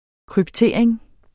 Udtale [ kʁybˈteˀɐ̯eŋ ]